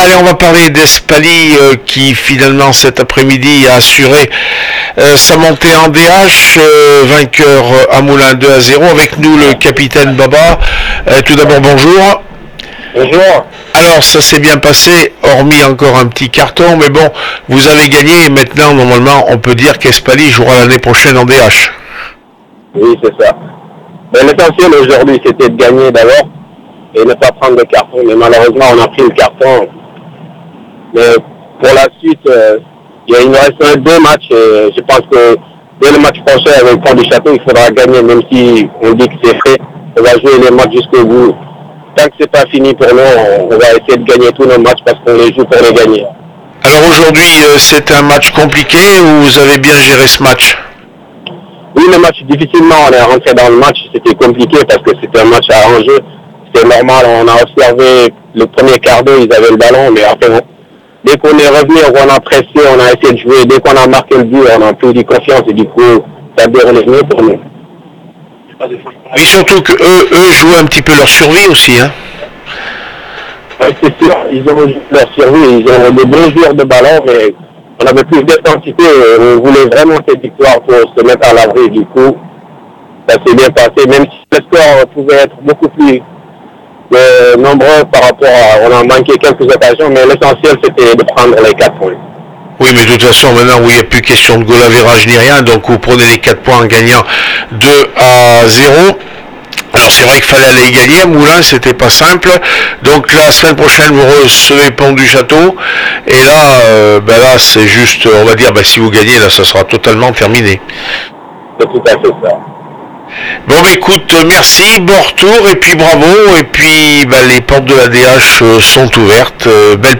15 mai 2016   1 - Sport, 1 - Vos interviews, 2 - Infos en Bref   No comments